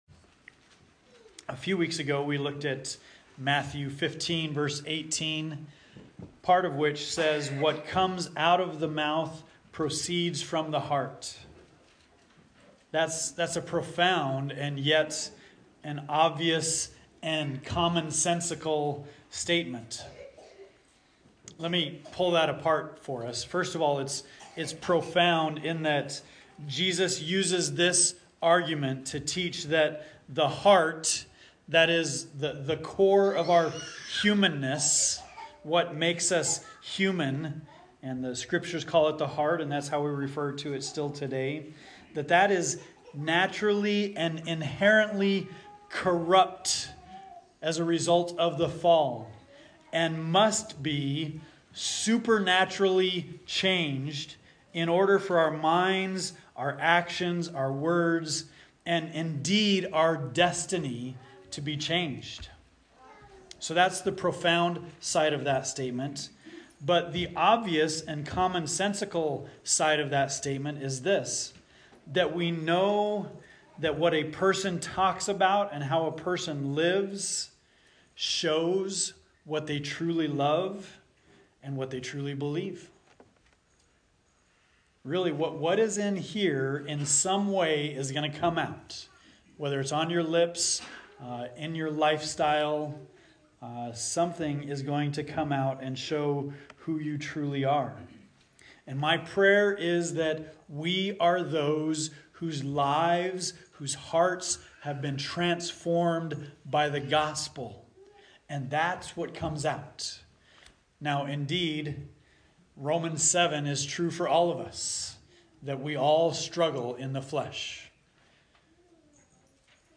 The Great Confession – Kadoka, Okaton, and Belvidere Churches